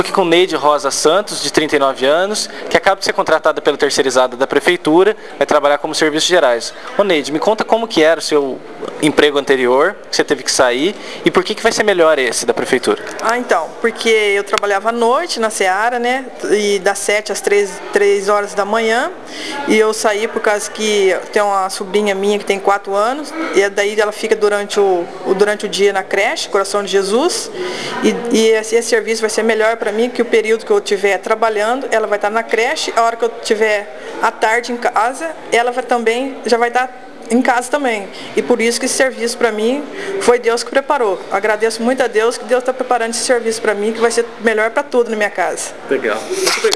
Confira depoimentos de três dos novos servidores prestadores de serviço para Educação.